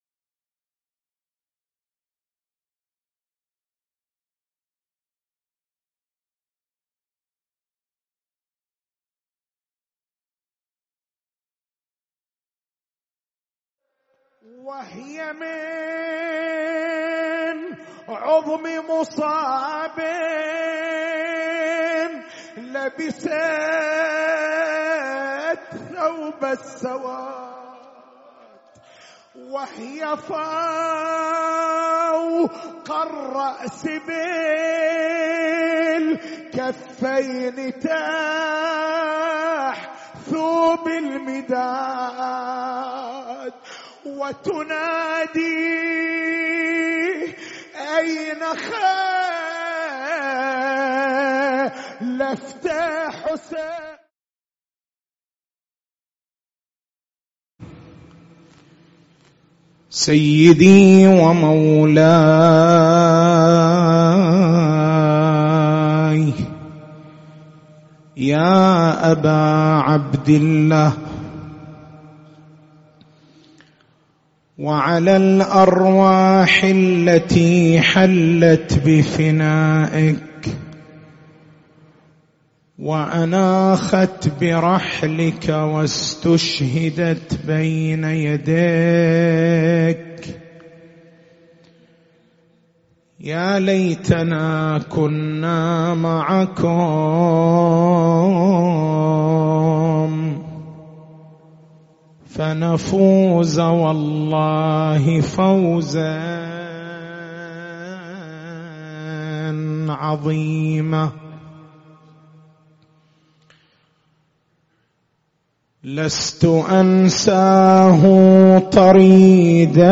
تاريخ المحاضرة: 02/01/1444 نقاط البحث: هل تأخر الظهور المهدوي؟ لماذا لم يتحقق الظهور المهدوي حتى الآن؟